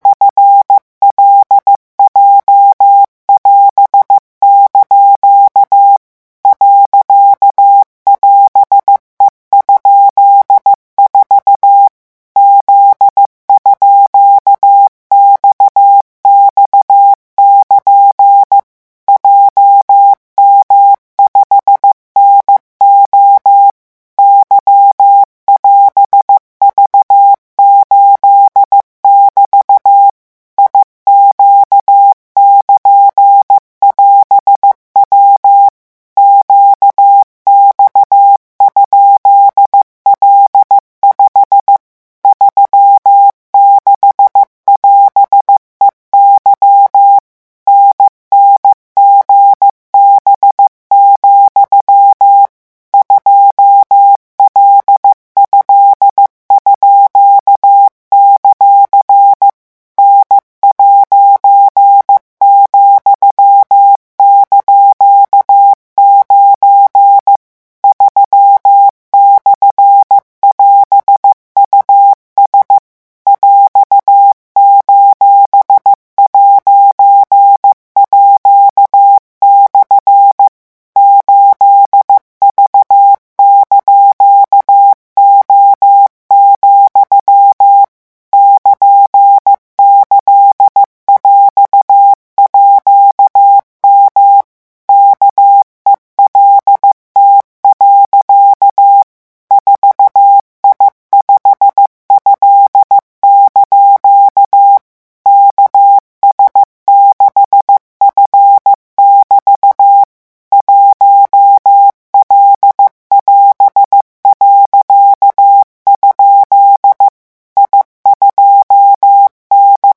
CODE MORSE-REVISION 16
C'est parti - vitesse de ... 10 mots minutes : 14 mots minutes : 18 mots minutes : 22 mots minutes : REVISION 16 - 10 mots minute REVISION 16 - 14 mots minute REVISION 16 - 18 mots minute REVISION 16 - 22 mots minute
revision16-vitesse_14_mots.mp3